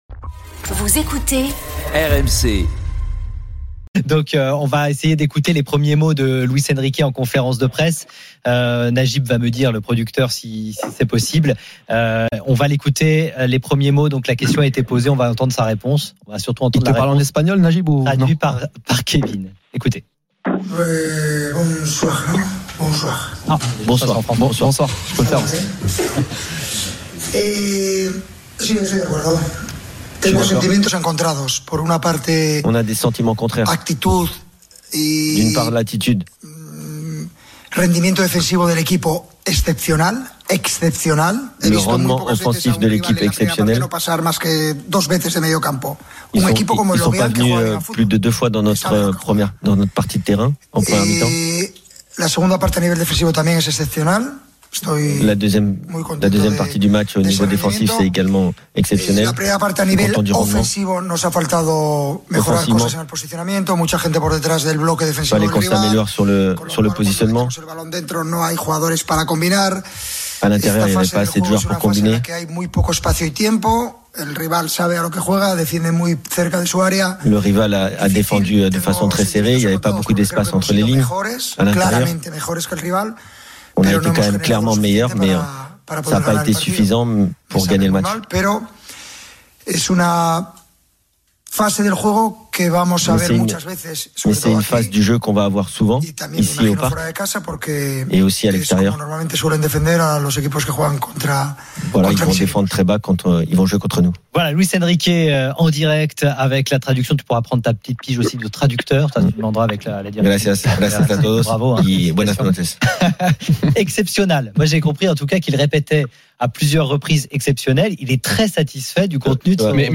Le Top de l'After Foot : Les premiers mots en conférence de presse de Luis Enrique après Lorient – 12/08